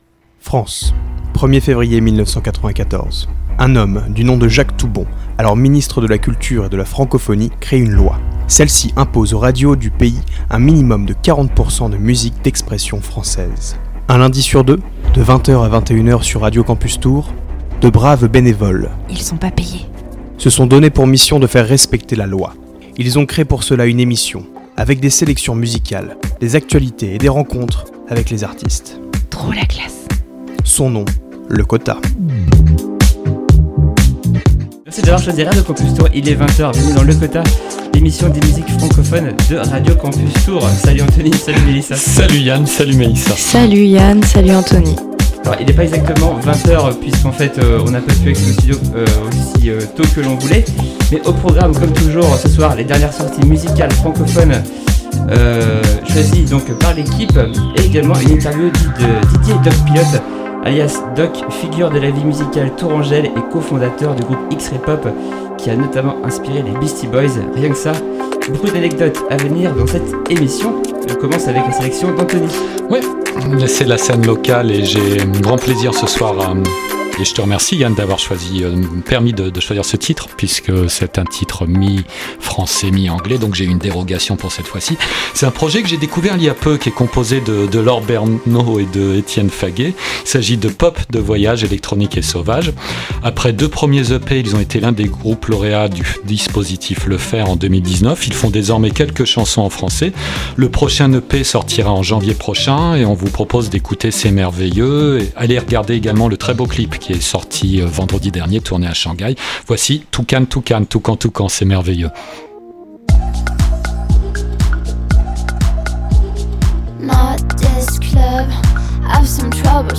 Le Quota, c’est le magazine des musiques francophones de Radio Campus Tours un lundi sur deux de 20h à 21h et en rediffusion le vendredi à 8h45.